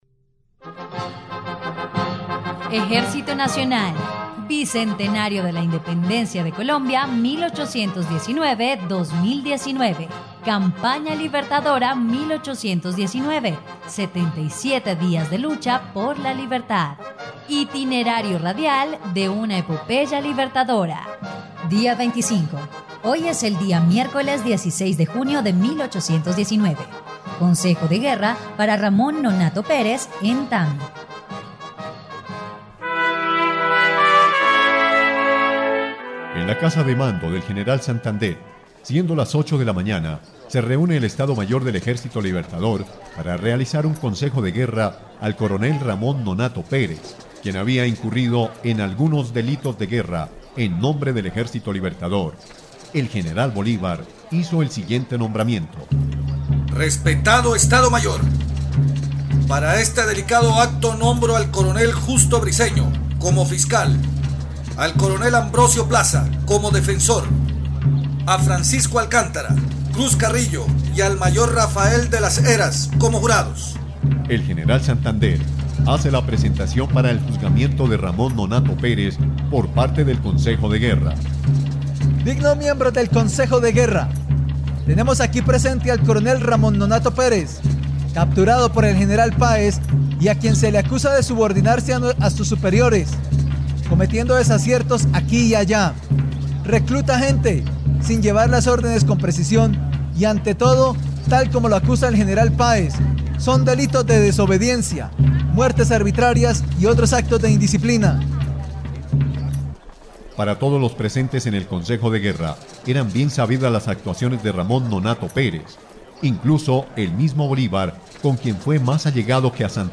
dia_25_radionovela_campana_libertadora.mp3.mp3